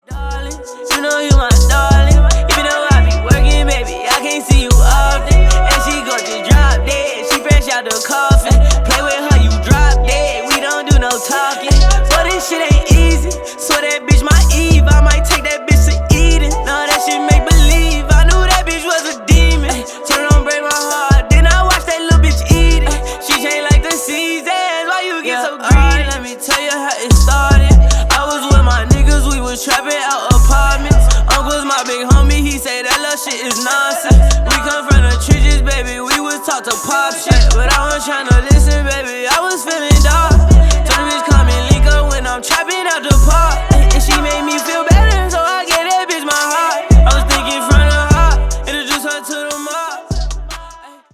• Качество: 256, Stereo
Хип-хоп
relax